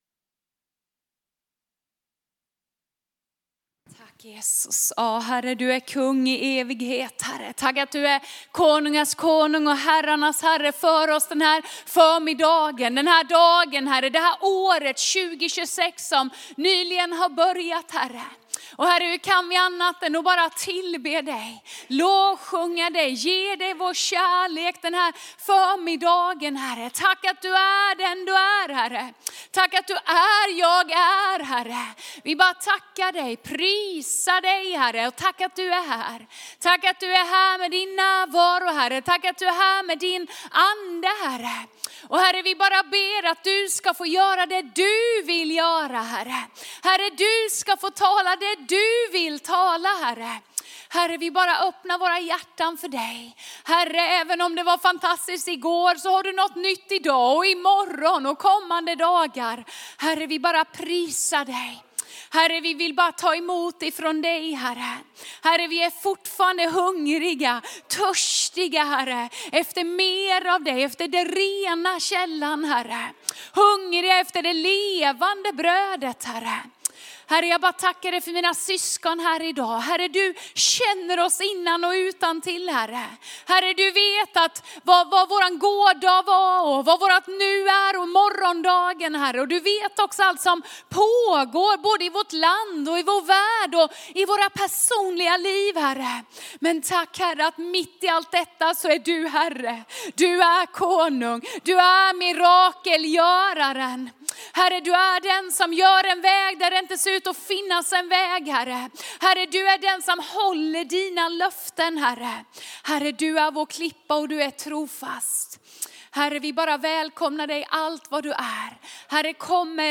Undervisning från Korskyrkan Alingsås på söndaghelaveckan.
Korskyrkan Alingsås söndag 11 januari 2026